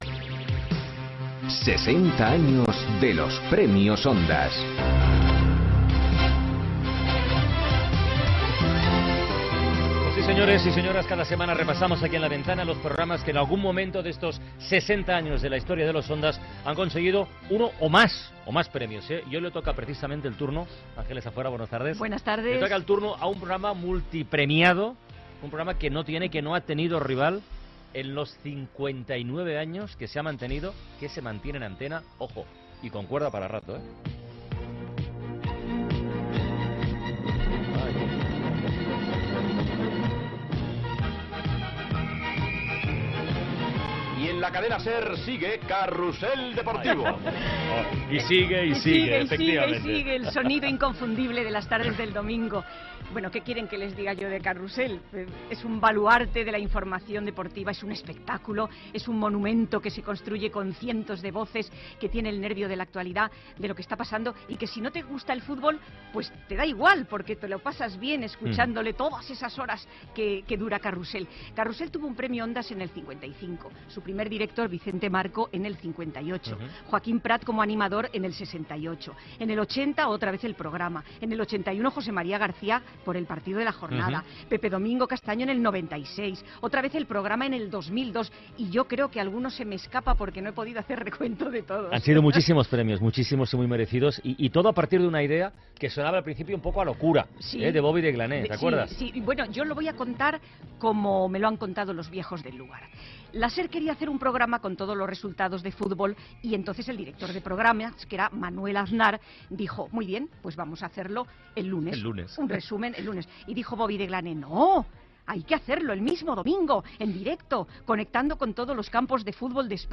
Emissora Ràdio Barcelona Cadena SER
Gènere radiofònic Entreteniment